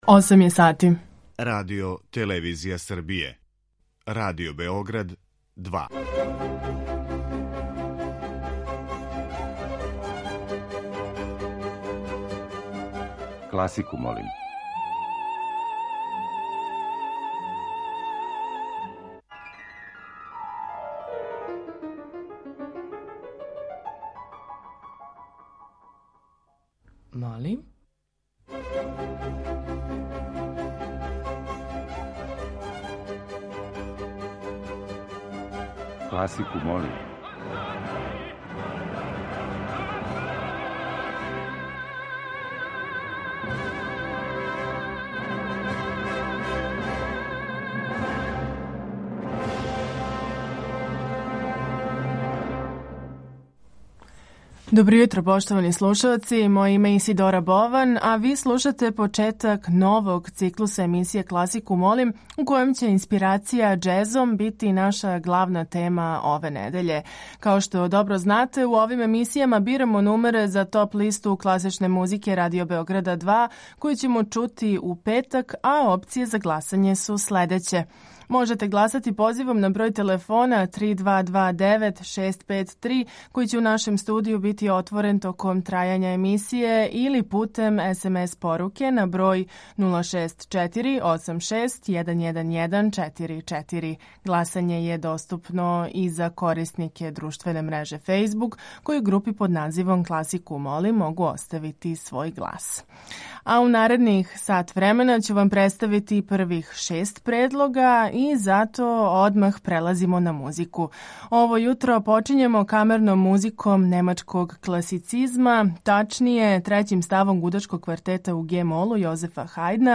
Тема циклуса je џез музика као инспирација композитора.